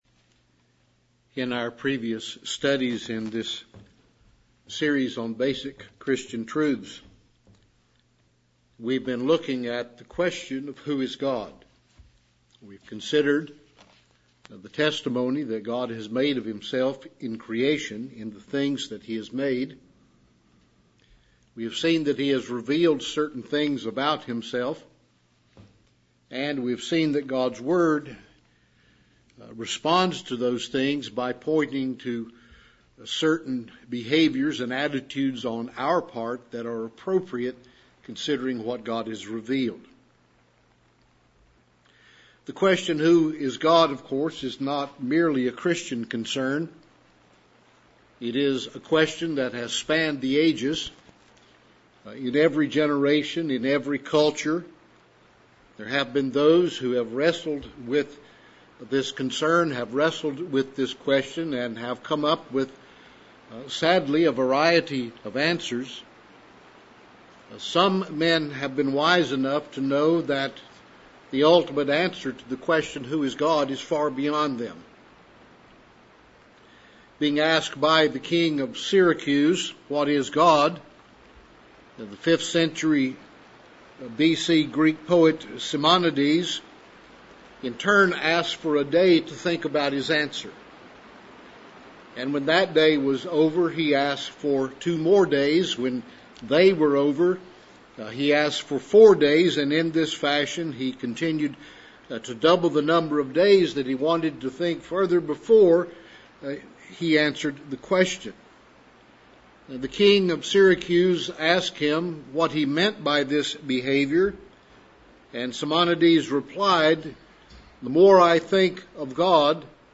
Basic Christian Truths Service Type: Evening Worship « 37 The Sermon on the Mount